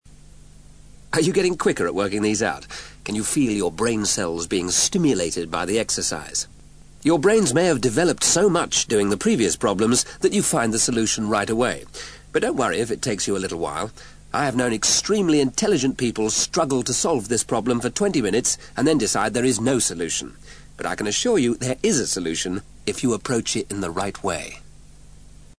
Finally, listen to the psychologist giving you some advice in logical thinking.